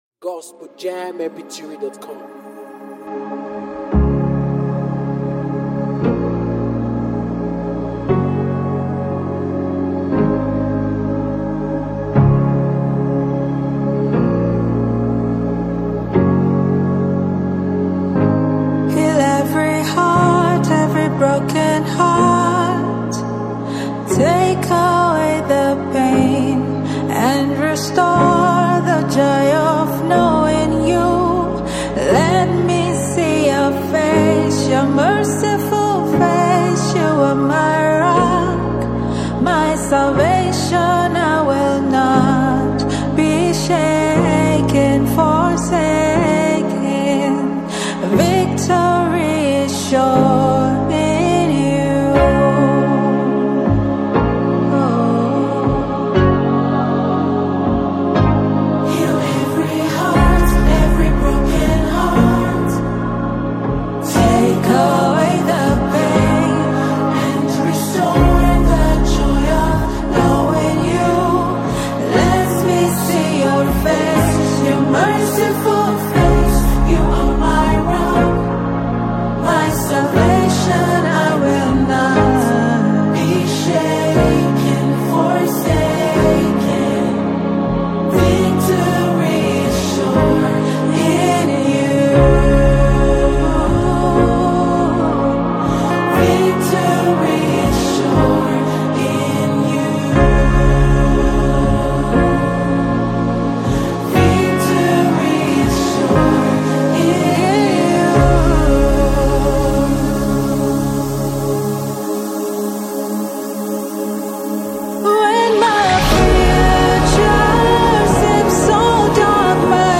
Female Gospel Afro